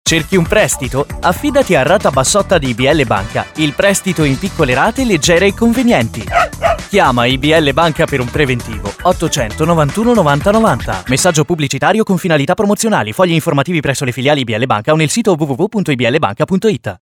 Italian voice talent for all tipes of productions: voice overs, documentaries, narrations, commercials
Sprechprobe: Werbung (Muttersprache):
Native italian young male voice